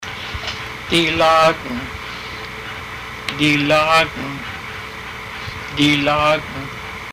Master Fluent Speaker